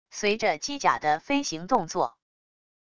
随着机甲的飞行动作wav音频